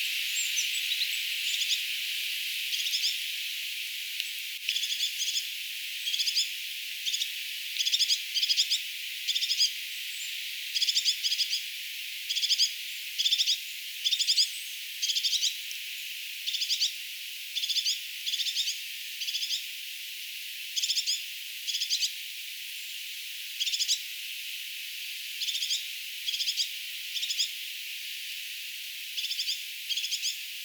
Video: laulujoutsenia peltoaukealla ��nite: tuollaista t�yht�tiaisen ��ntely�
tuollaista_toyhtotiaisen_aantelya.mp3